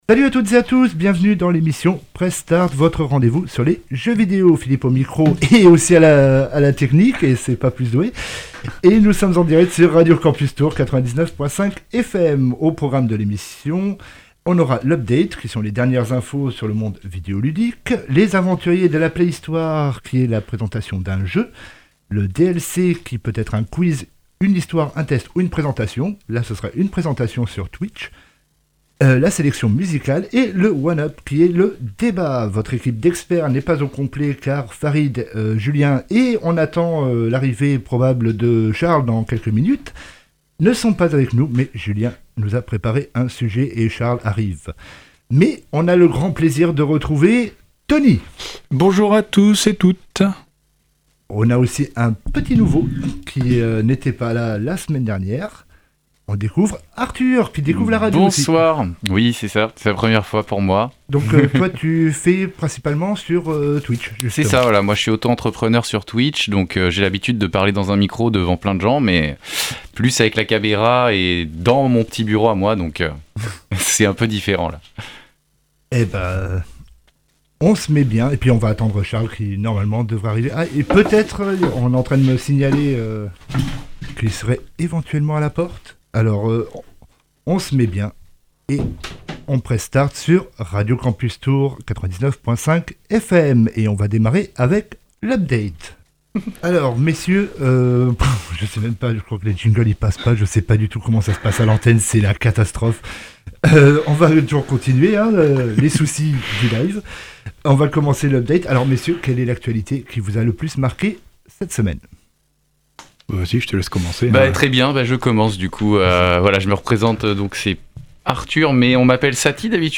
PressStart, c’est votre nouvelle émission Jeux Vidéo, chaque Mercredi, de 19h à 20h! Au programme de cette nouvelle émission, L’Update qui regroupe l’actu de la semaine, 1 UP qui est le débat du jour autour du jeu vidéo et Les Aventuriers de la Playhistoire qui est notre test de jeu.